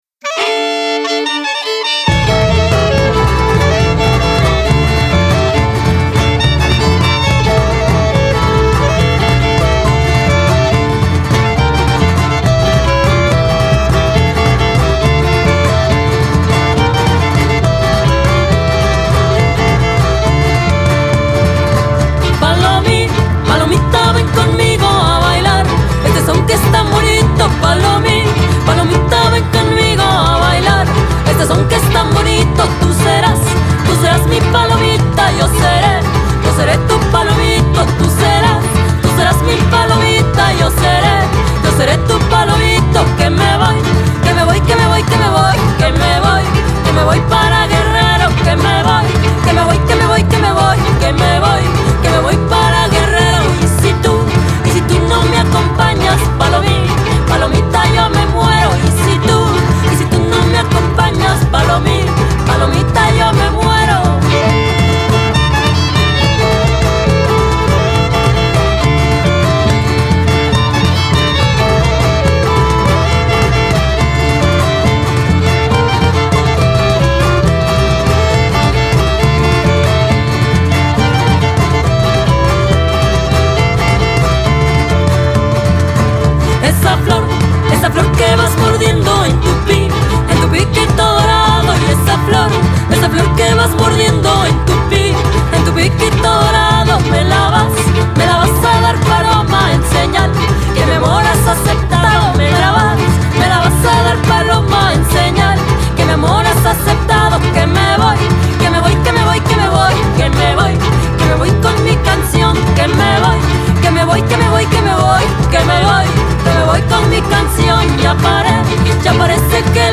Chilenas, gustos, boleros, polkas y sones con el estilo de La Yerbabuena en la Fonoteca Nacional